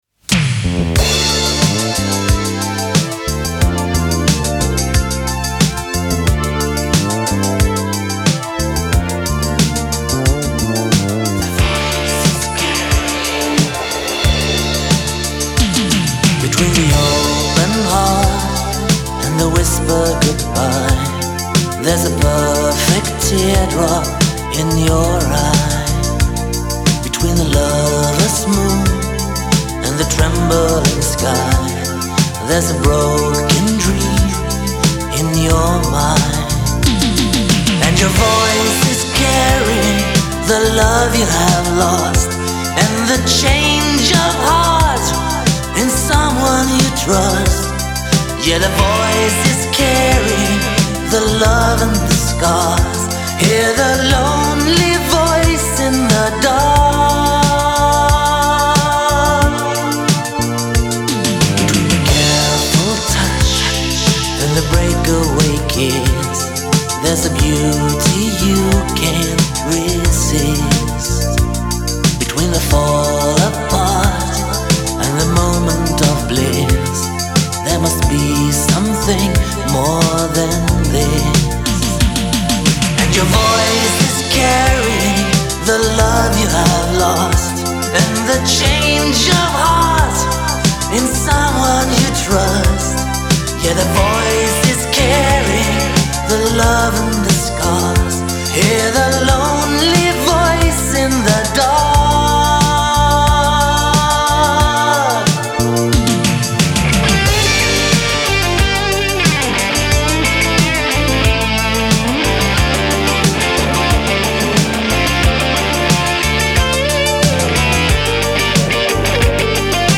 В этих двух песнях слышна драм-машинка
И кажется- эти две песни- просто демки, но звучит классно